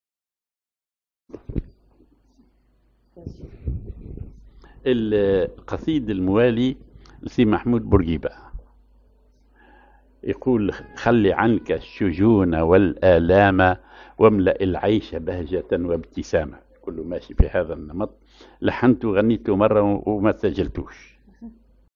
genre أغنية